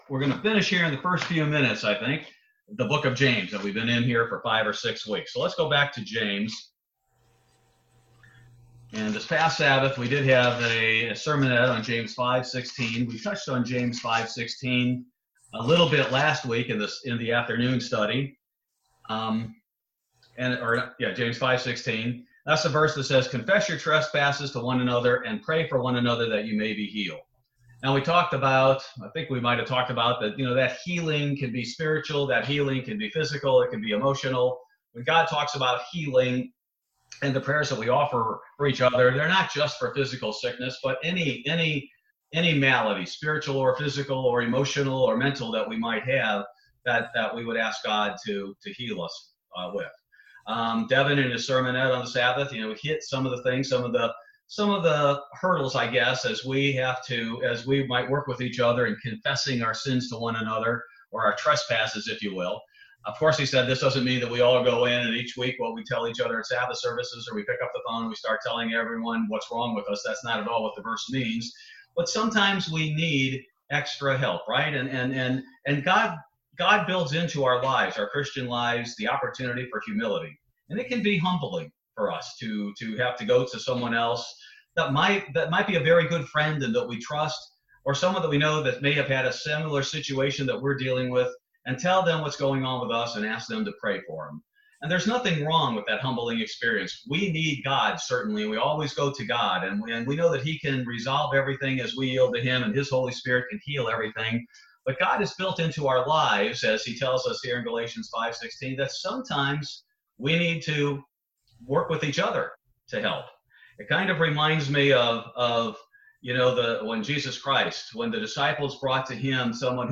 Bible Study June 17, 2020